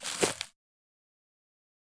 footsteps-single-outdoors-002-01.ogg